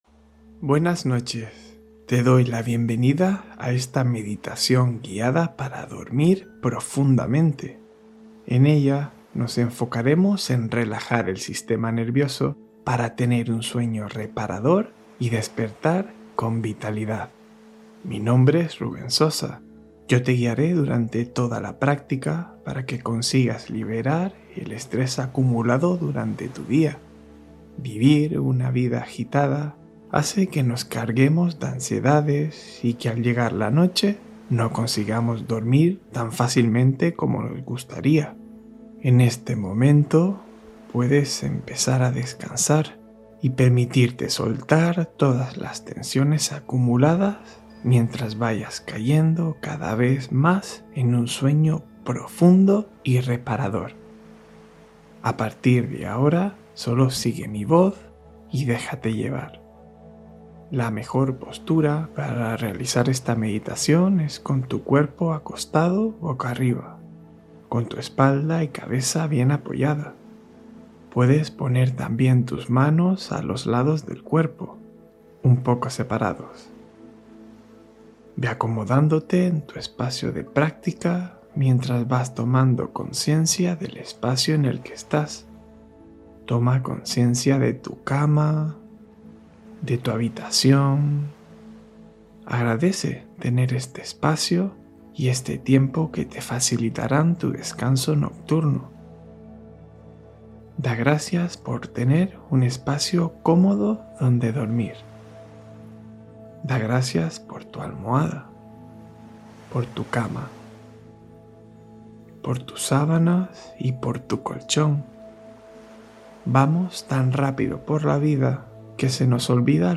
Duerme con Sensación de Bienestar: Meditación Guiada con Olas